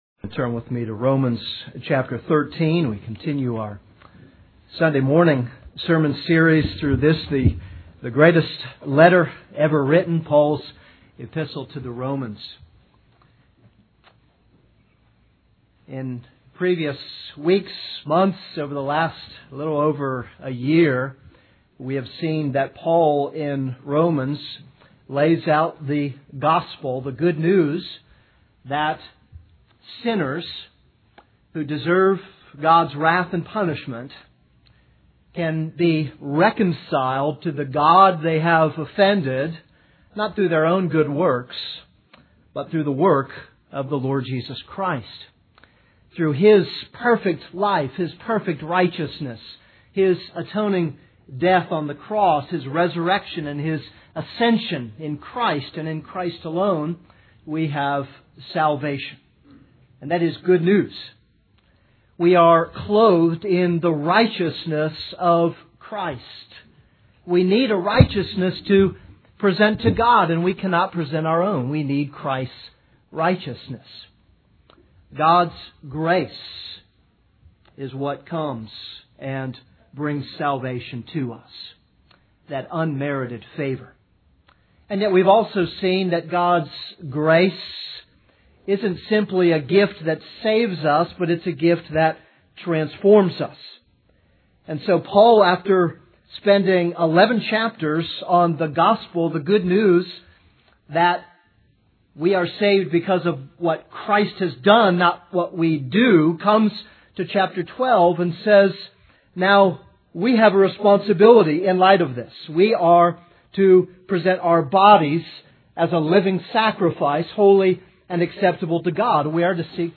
This is a sermon on Romans 13:8-14.